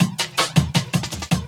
12 LOOP12 -L.wav